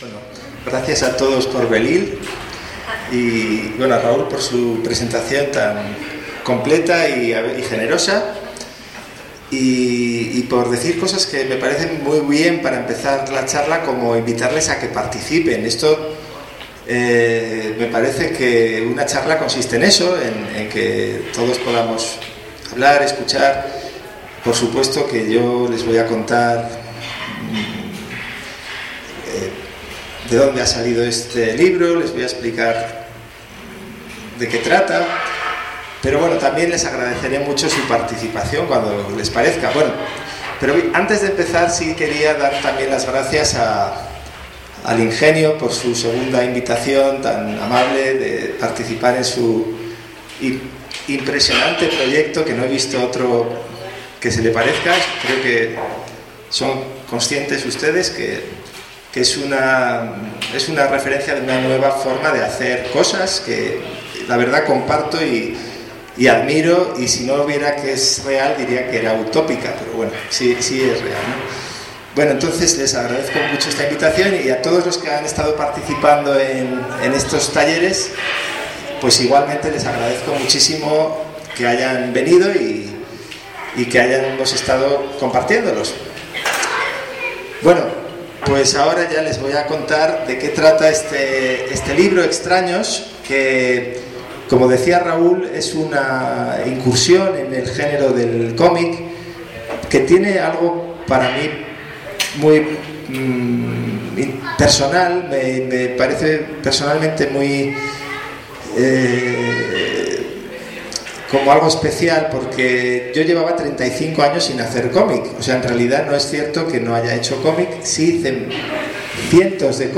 Presentación del libro Extraños
Archivosonoro Lugar: San Cristóbal de Las Casas, Chiapas. Hora: 17:00 horas Equipo: Grabadora digital SONY ICD-UX80 Fecha: 2014-11-09 20:38:00 Regresar al índice principal | Acerca de Archivosonoro